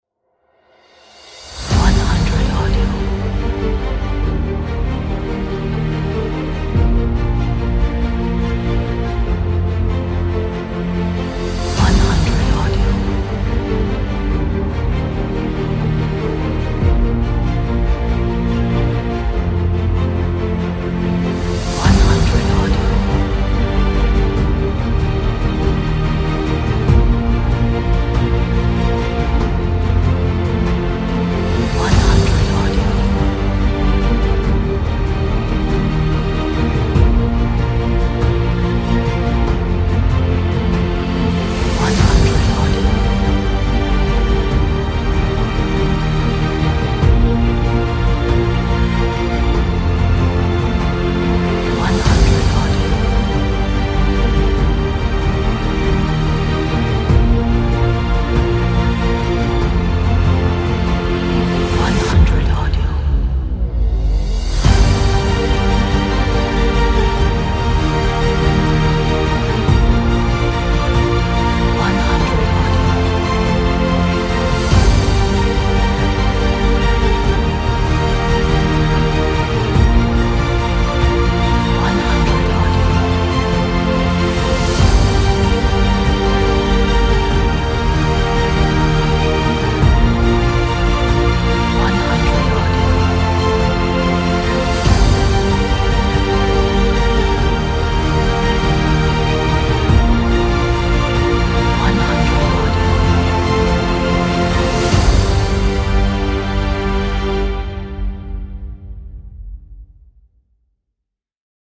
Cinematic and inspiring epic track.